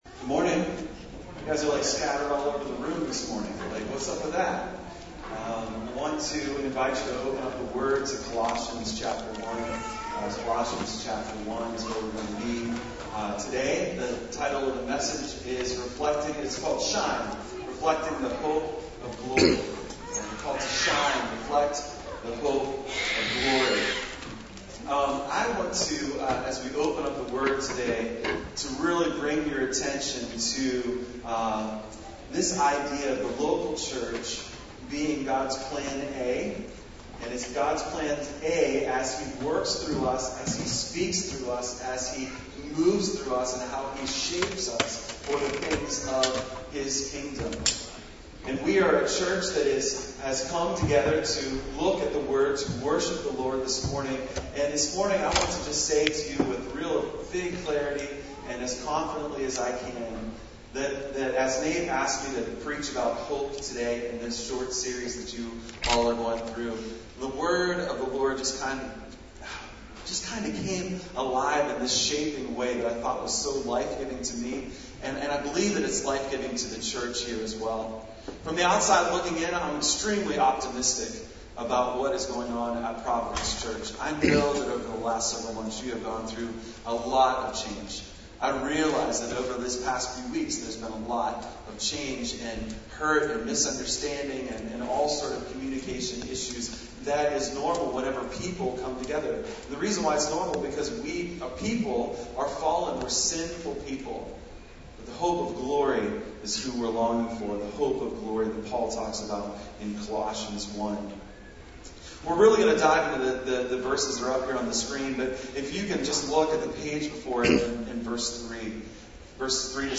NOTE: Due to issues at the time of recording, the audio quality of this sermon is not up to our usual standards.